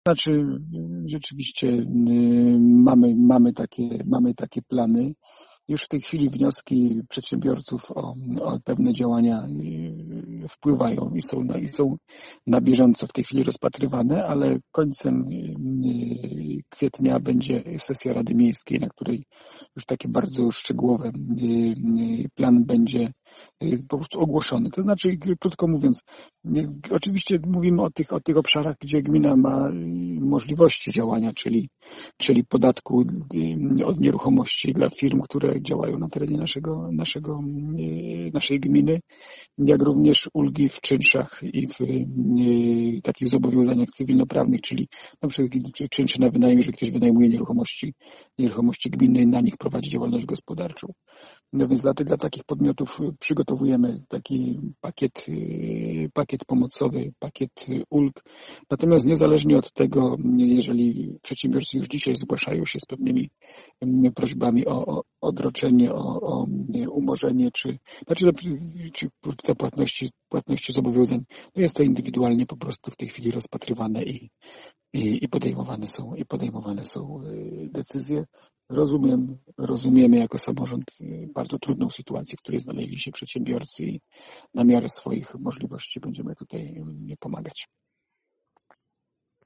Posłuchaj burmistrza Jacka Lelka: